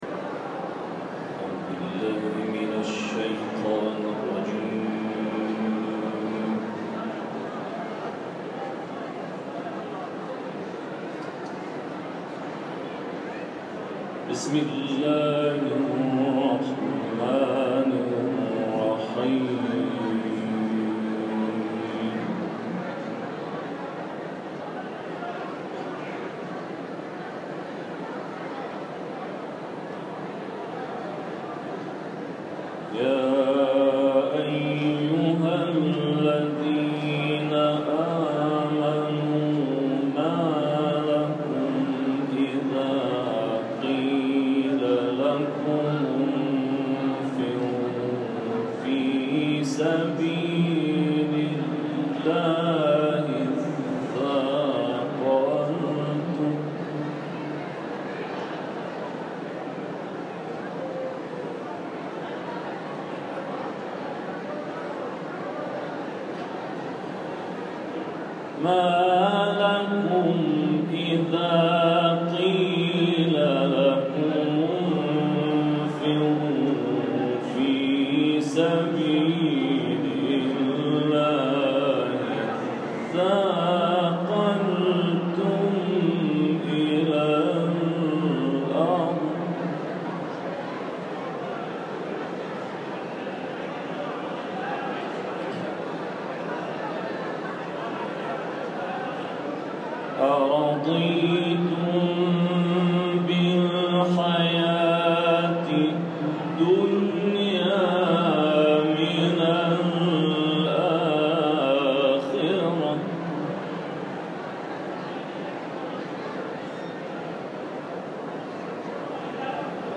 تلاوت اذانگاهی
تلاوت